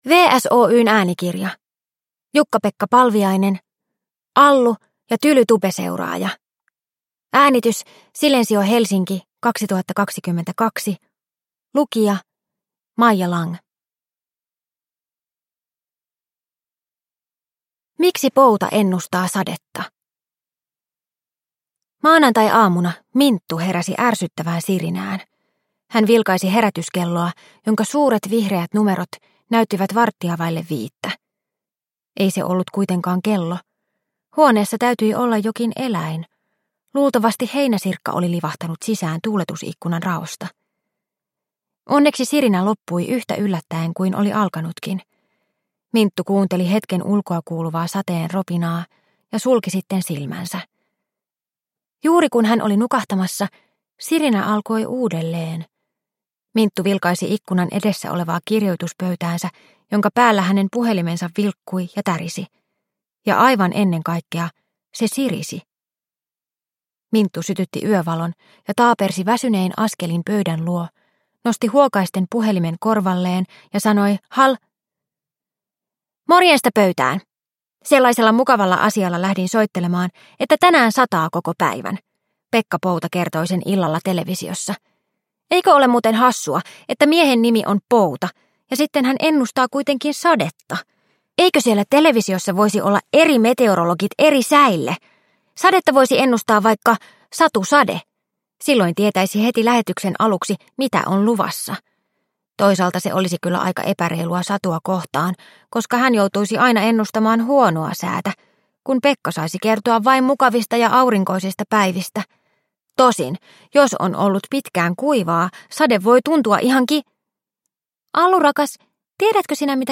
Allu-sarjan kuudes osa ilmestyy äänikirjana.